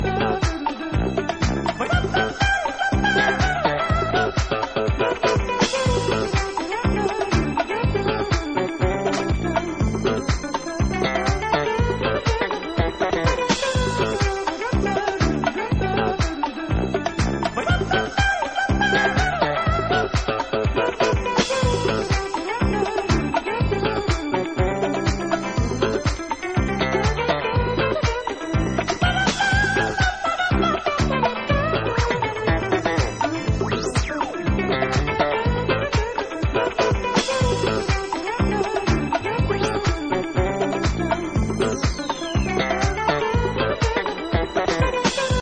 supplier of essential dance music
Disco